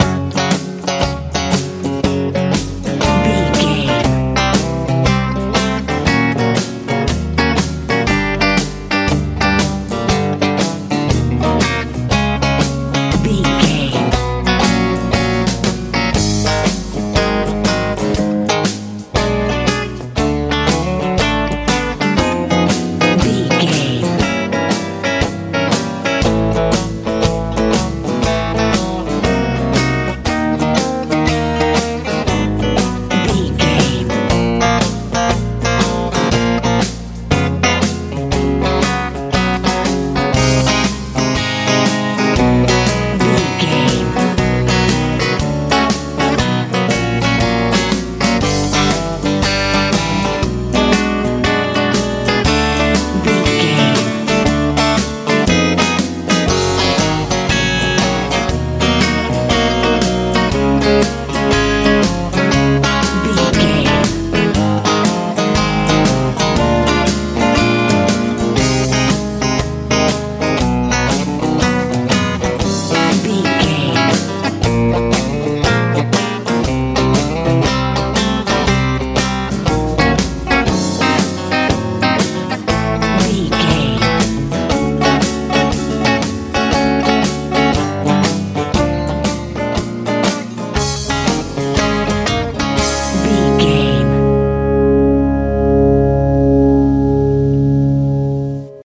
country boogie feel
Ionian/Major
F♯
joyful
acoustic guitar
electric guitar
bass guitar
drums
lively
light
energetic